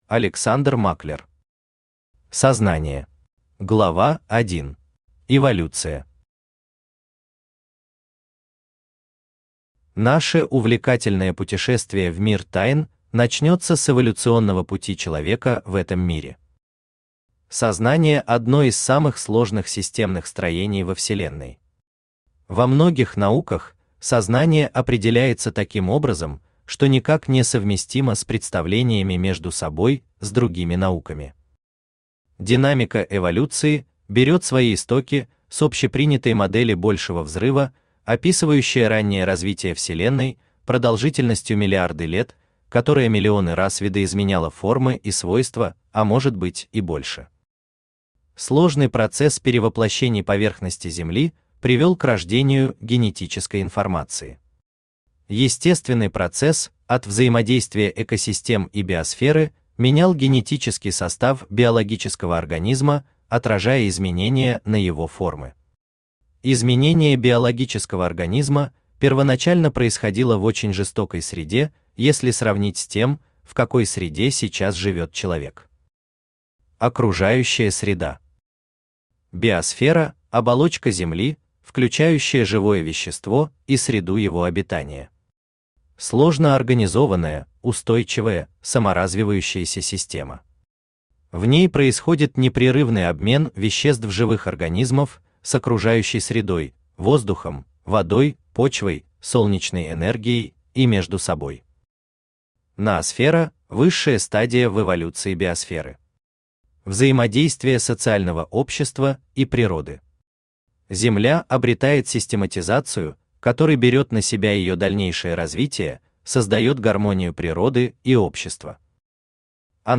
Аудиокнига Сознание | Библиотека аудиокниг
Aудиокнига Сознание Автор Александр Германович Маклер Читает аудиокнигу Авточтец ЛитРес.